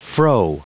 Prononciation du mot fro en anglais (fichier audio)
Prononciation du mot : fro